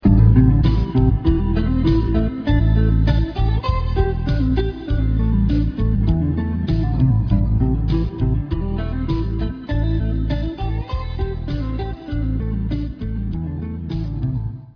Estudios de guitarra
Se trata de ejercicios específicos y progresivos para guitarra solista pulsada con púa, de nivel medio, presentados en notación musical y con ejemplo de sonido en formato MIDI o REAL AUDIO.
Los cuatro suiguientes son ejercicios de ligados.